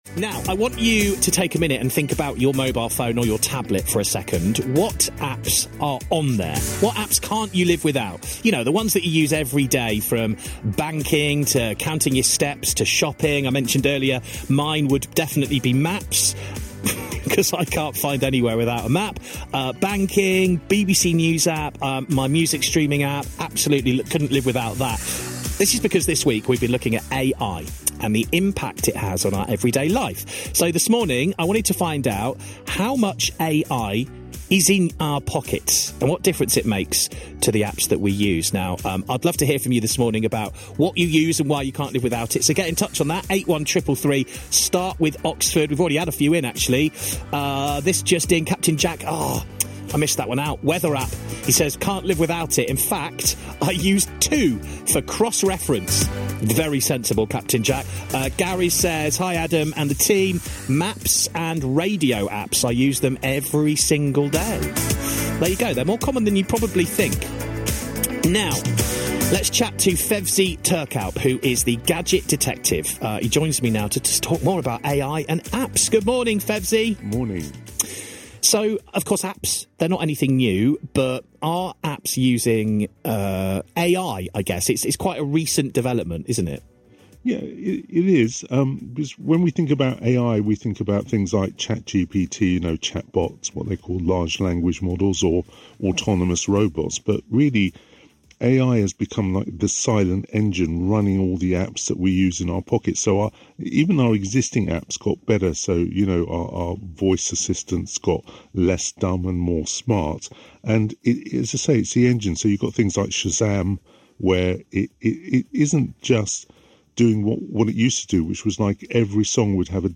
tech news broadcasts